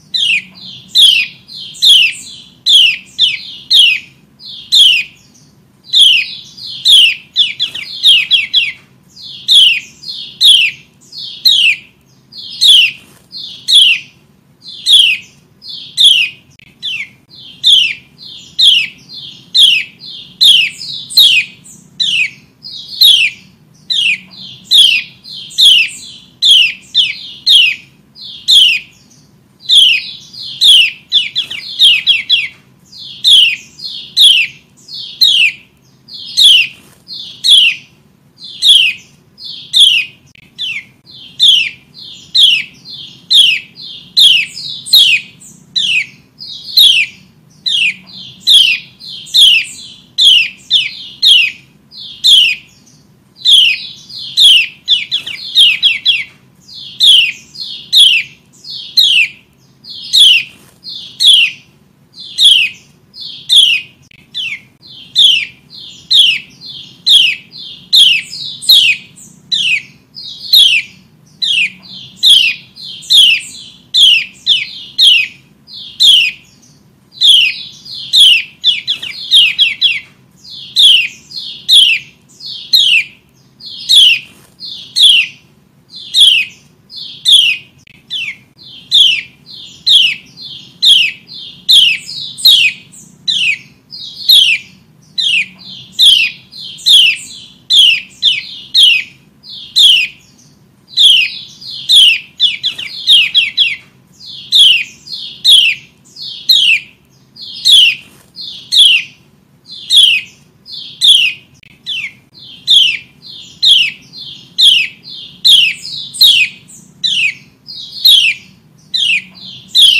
Suara Burung Kecial Kuning Betina
Kategori: Suara burung
Keterangan: Suara ciak ciak kecial kuning betina yang ampuh memanggil jantan untuk nyaut ribut, cocok untuk memperdengarkan kepada kecial jantan.
suara-burung-kecial-kuning-betina-id-www_tiengdong_com.mp3